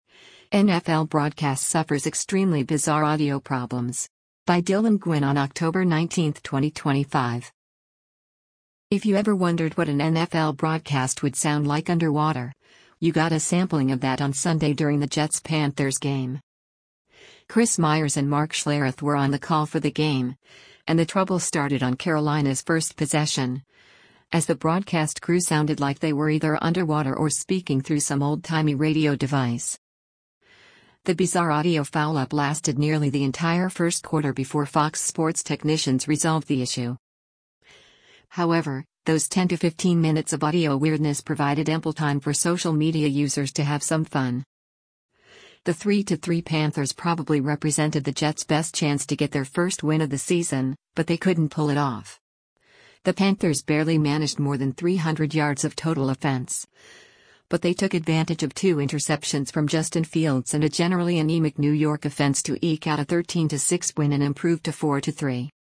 NFL Broadcast Suffers Extremely Bizarre Audio Problems
If you ever wondered what an NFL broadcast would sound like underwater, you got a sampling of that on Sunday during the Jets-Panthers game.
Chris Myers and Mark Schlereth were on the call for the game, and the trouble started on Carolina’s first possession, as the broadcast crew sounded like they were either underwater or speaking through some old-timey radio device.
The bizarre audio foul-up lasted nearly the entire first quarter before Fox Sports’ technicians resolved the issue.